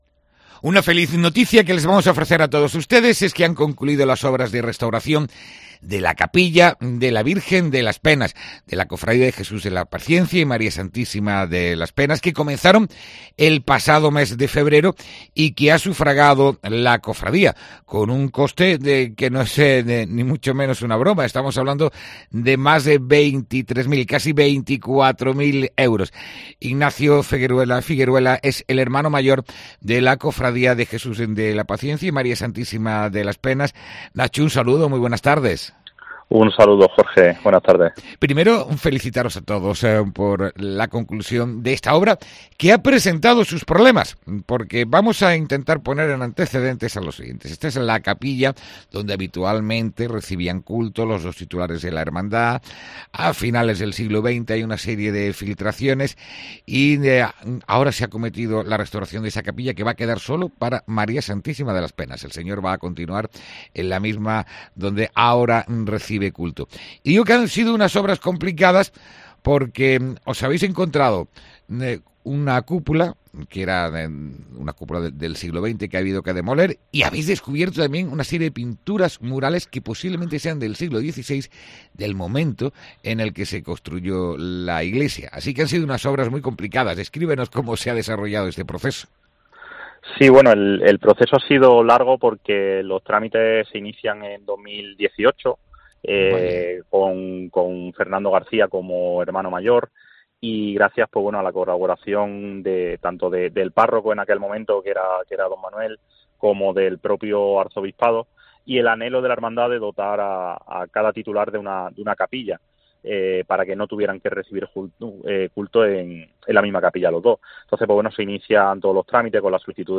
ENTREVISTA| Ha sido necesario demoler una cúpula para restaurar la capilla de la Virgen de las Penas